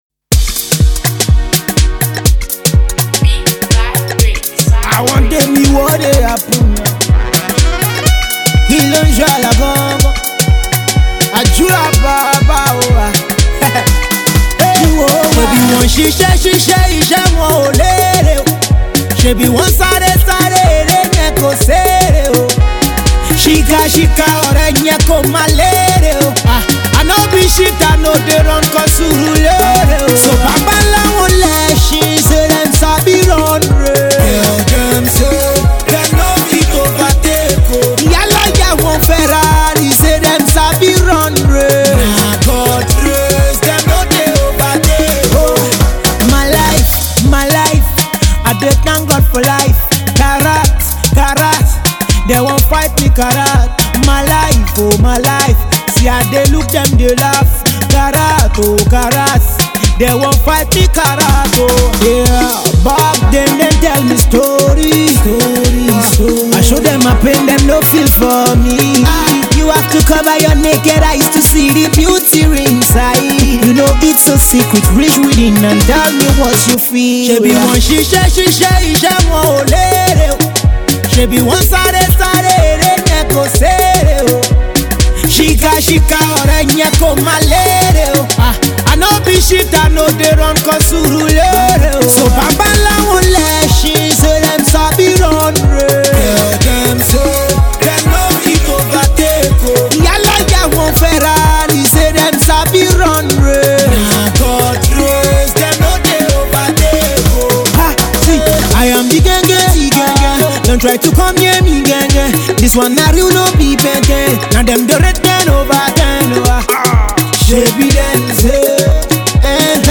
leans heavily on Fuji-pop